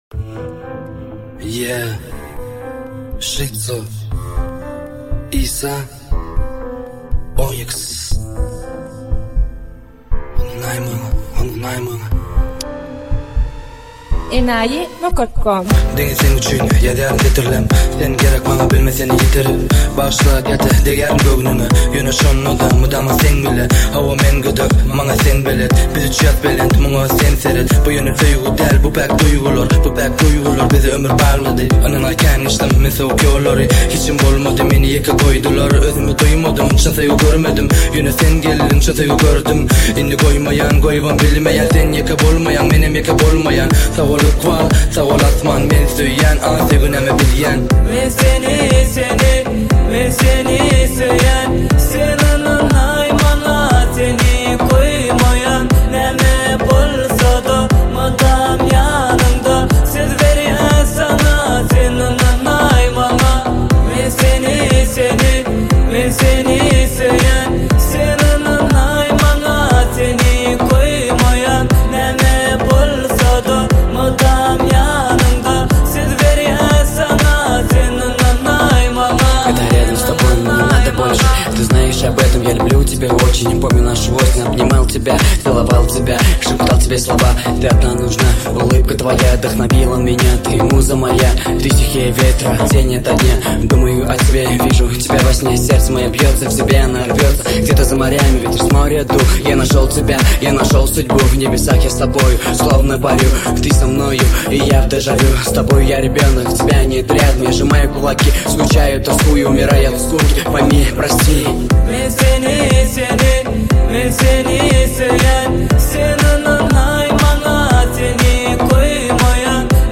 Туркменские песни